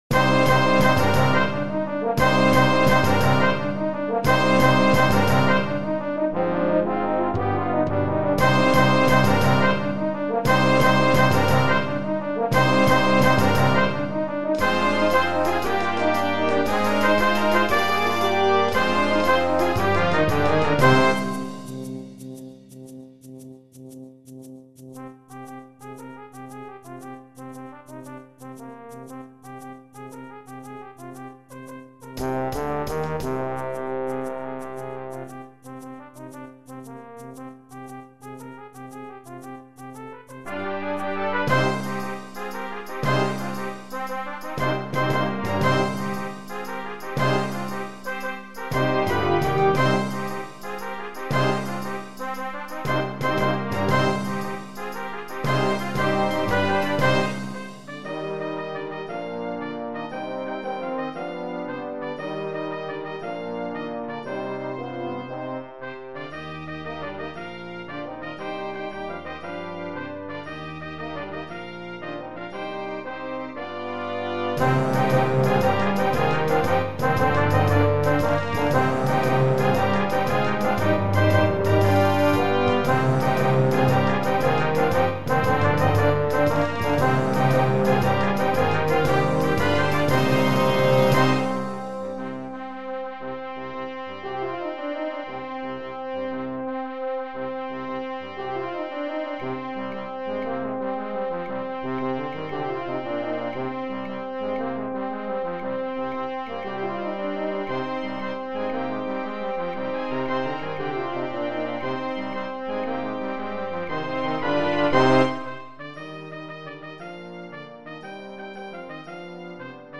Voicing: 14 Brass